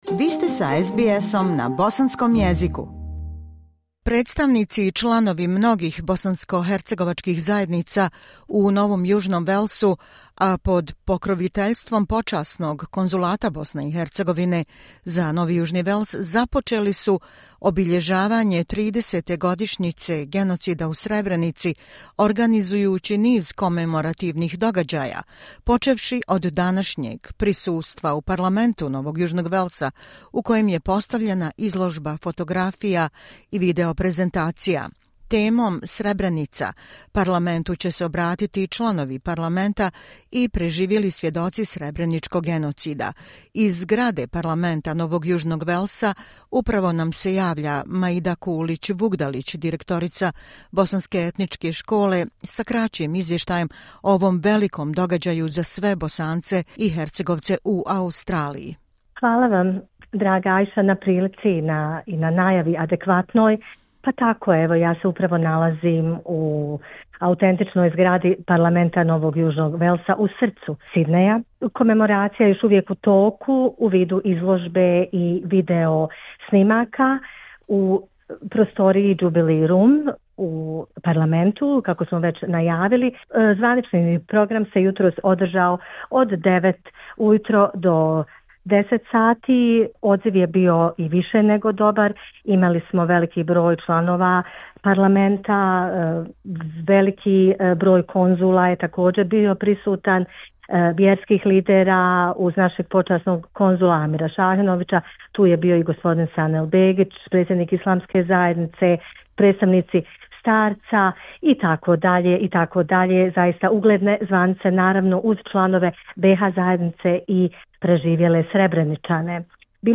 Iz zgrade Parlamenta NJW, sa lica mjesta